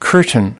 20. curtain (n) /ˈkɜːrtn/: màn cửa, rèm cửa